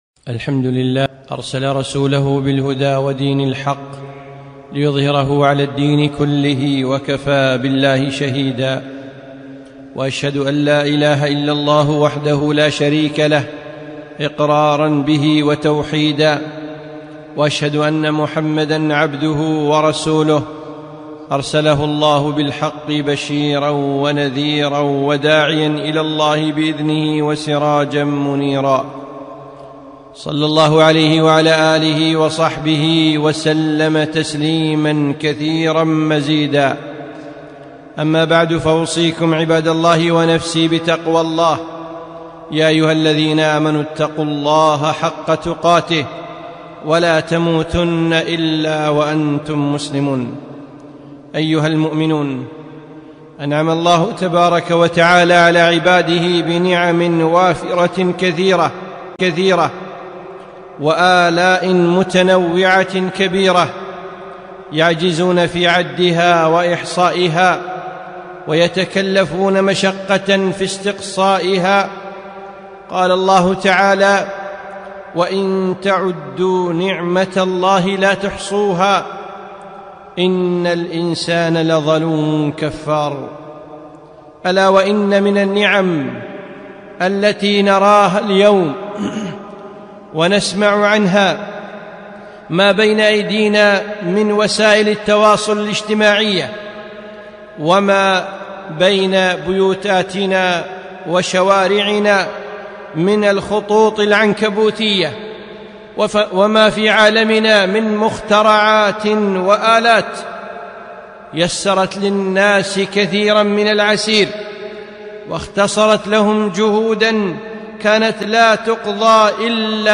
خطبة - جرائم إلكترونية